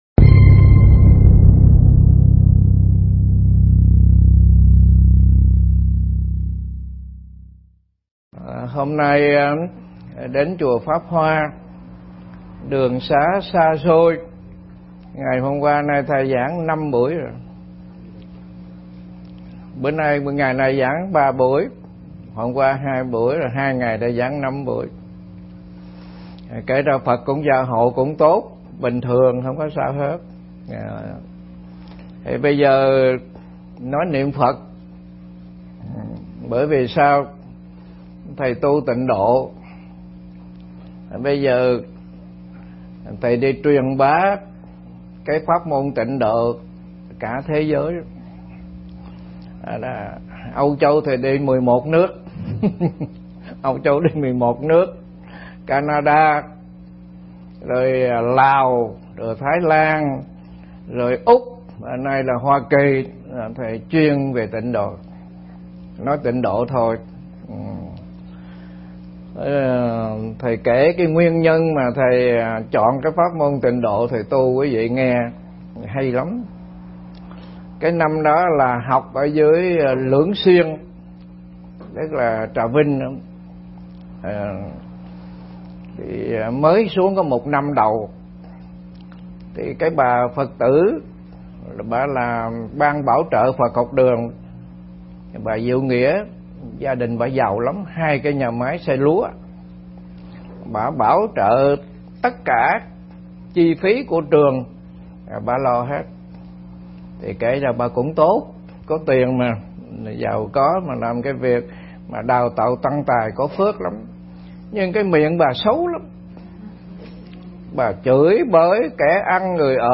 Nghe Mp3 thuyết pháp Kể Chuyện Tâm Linh
Mp3 Thuyết pháp Kể Chuyện Tâm Linh